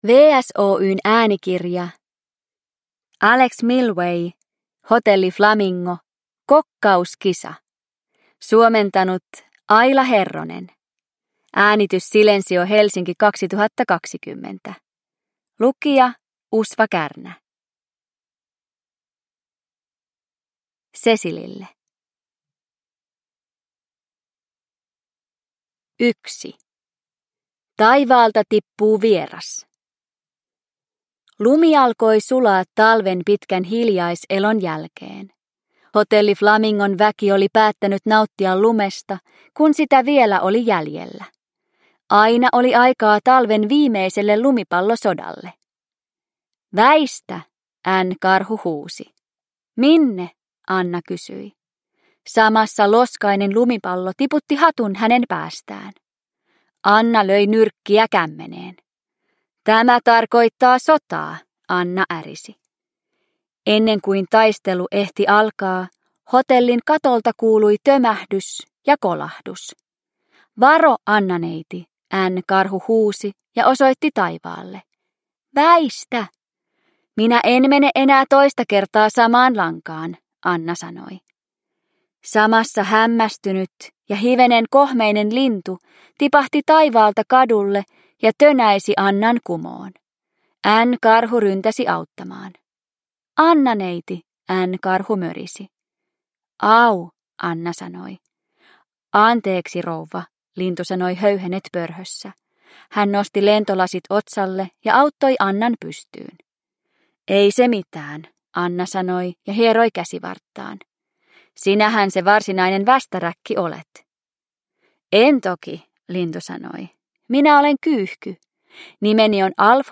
Hotelli Flamingo: Kokkauskisa – Ljudbok – Laddas ner